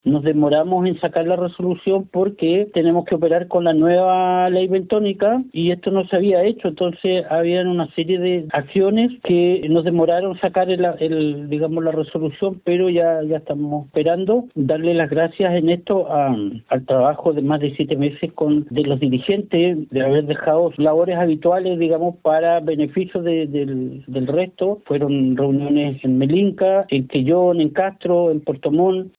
La biomasa total autorizada alcanza las 14.000 toneladas, de las cuales 8.102 corresponden a cuotas compartidas entre ambas regiones. La temporada de extracción se mantendrá abierta hasta el 15 de octubre. Así lo detalló el director zonal de pesca, Alberto Millaquén.
director-zonal-de-pesca-.mp3